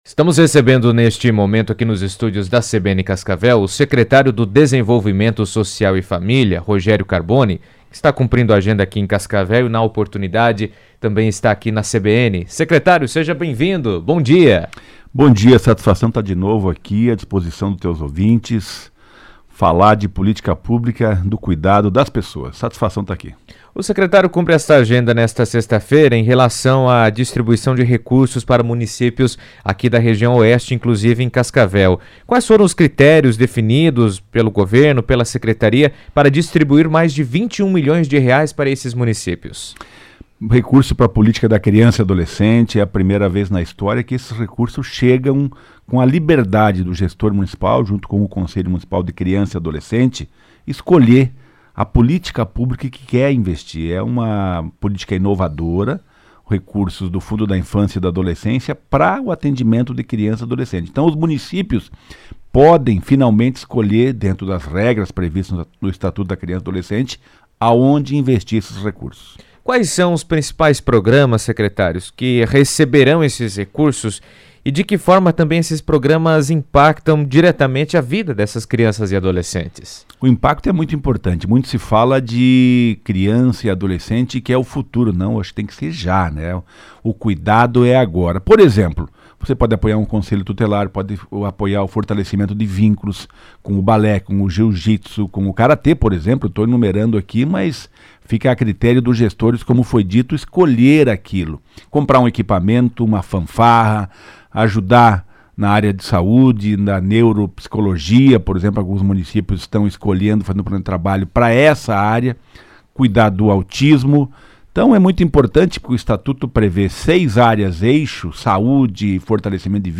Em entrevista à CBN Cascavel, o secretário destacou a importância dos repasses para ampliar o alcance das políticas públicas voltadas à infância e juventude na região.